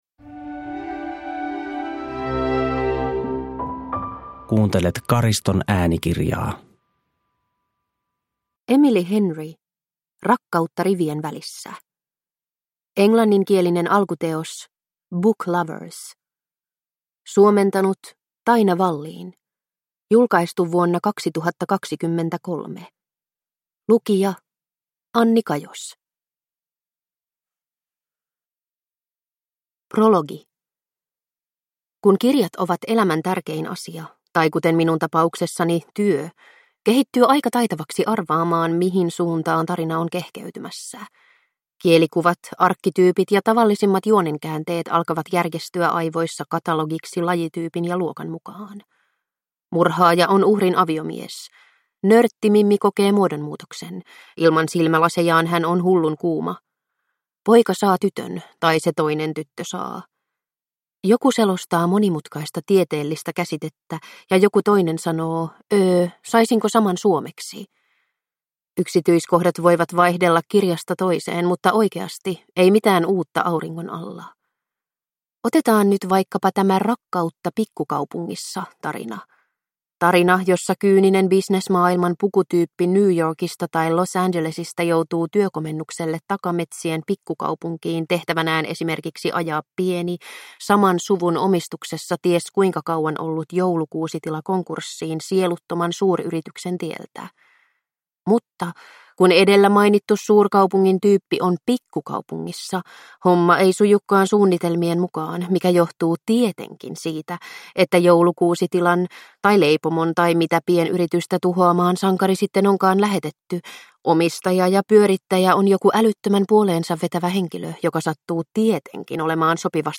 Rakkautta rivien välissä – Ljudbok – Laddas ner